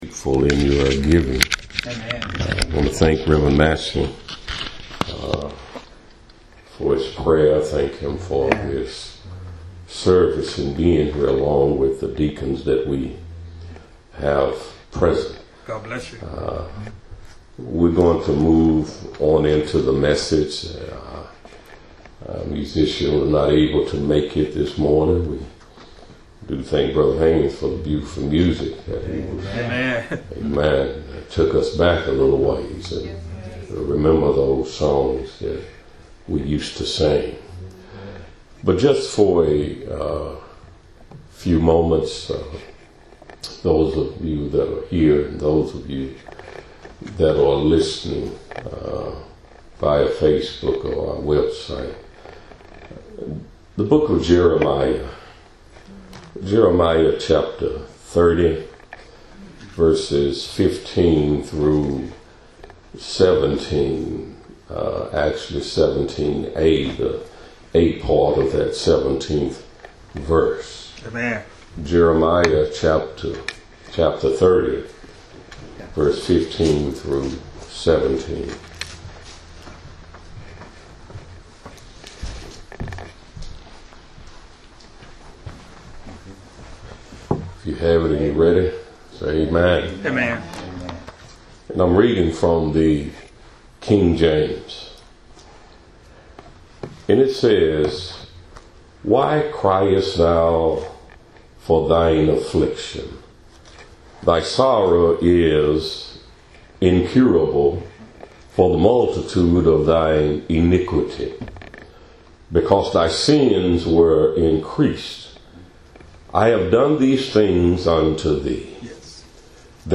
Audio Sermons - Fourth Ward Missionary Baptist Church